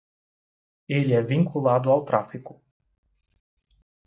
Lees meer Betekenis (Engels) Verb Adj vincular to bind to link Lees meer Vertalings keyed Uitgespreek as (IPA) /vĩ.kuˈla.du/ Boekmerk dit Verbeter jou uitspraak Notes Sign in to write sticky notes